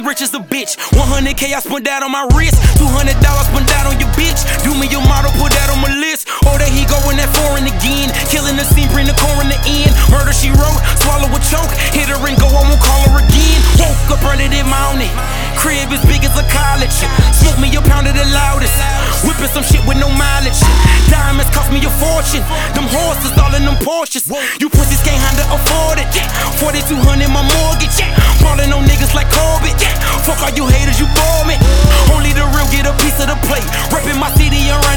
Hardcore Rap
Dirty South
Жанр: Хип-Хоп / Рэп